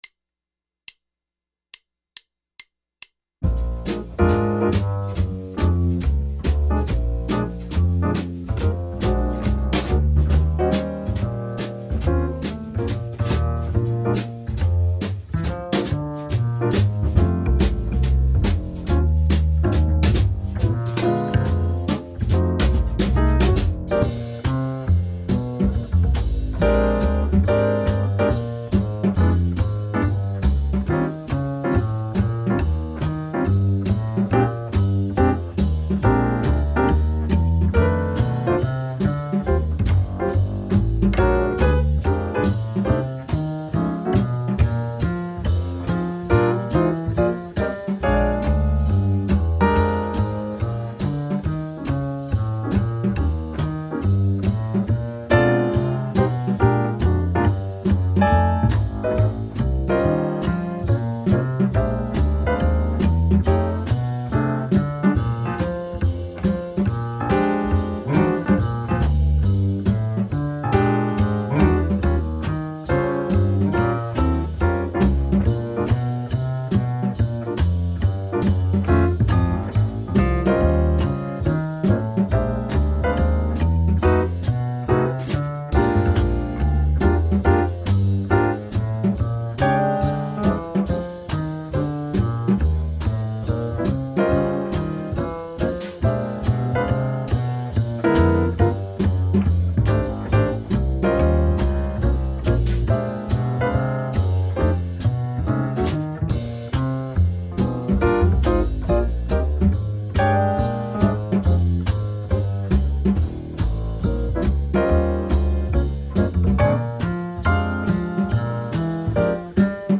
Blues Guitar Sidestepping Workout